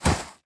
Index of /App/sound/monster/ice_snow_witch
drop_1.wav